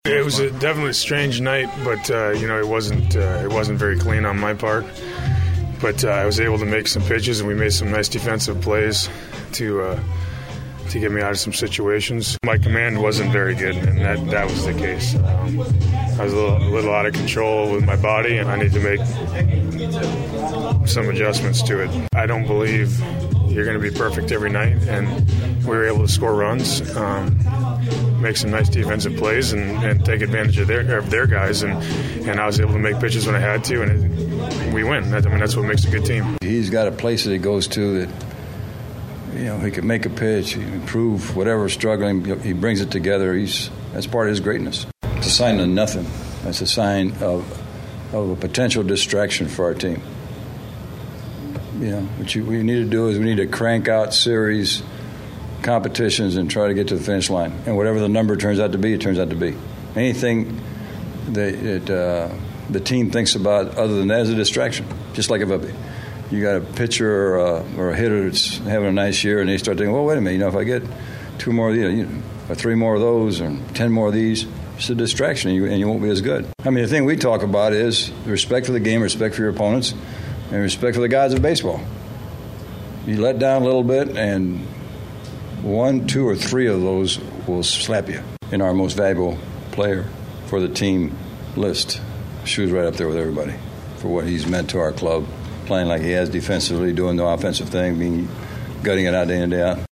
Below are comments from Carpenter, and Manager Tony LaRussa.